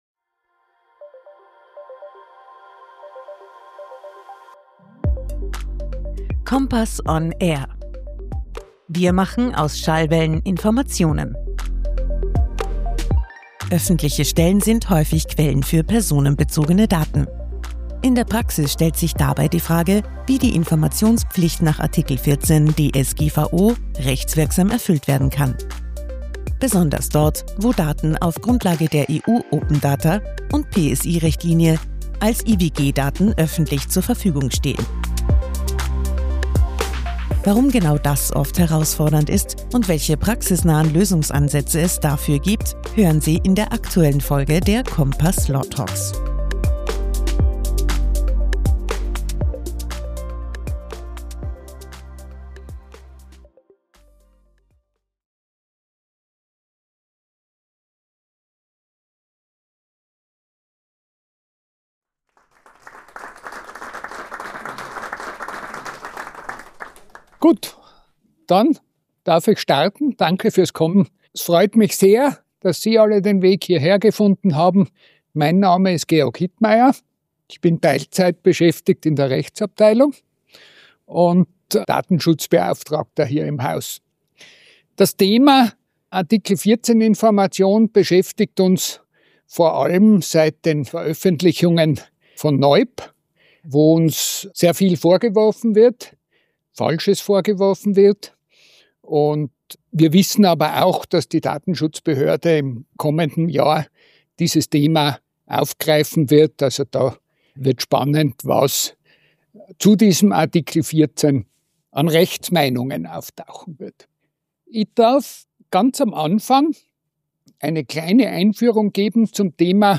In dieser Bonusfolge der Compass Law Talks, live aufgenommen im Compass CUBE, besprechen wir das Spannungsfeld zwischen Open Data, Public Sector Information (PSI) und Datenschutzrecht.